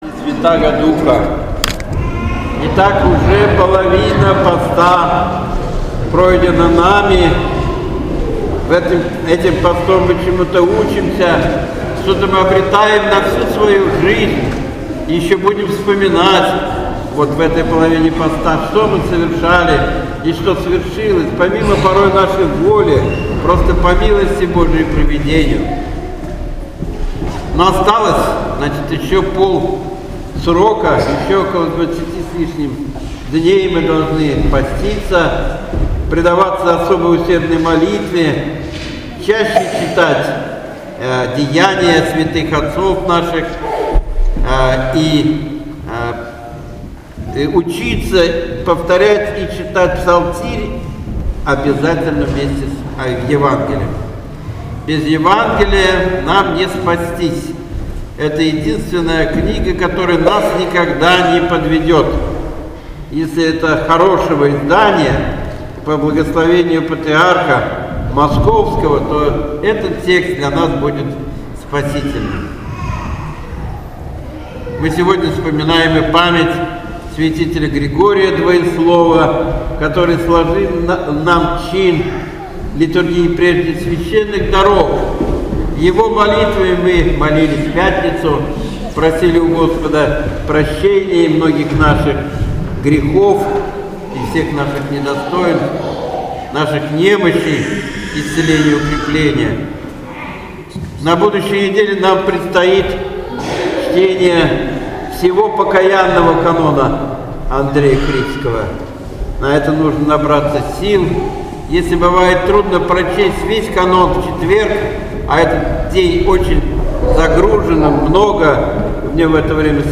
Божественная Литургия 26 марта 2017 года.